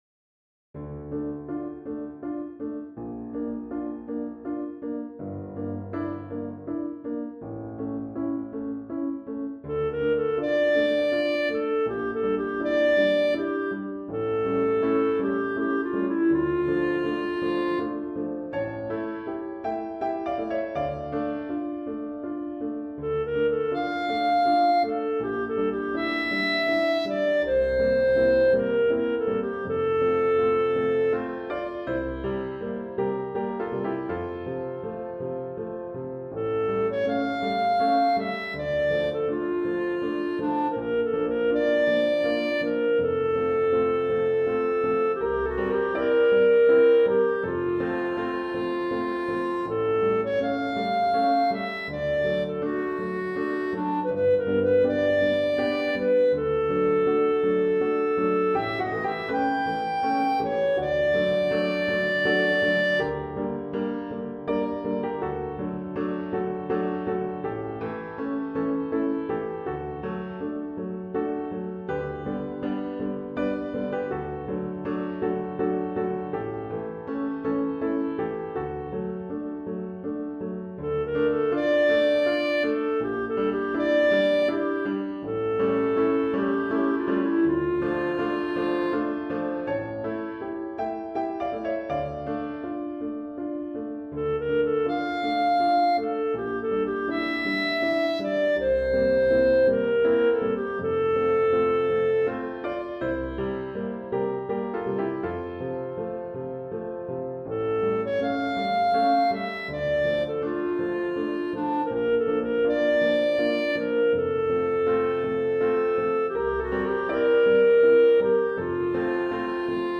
for voice and piano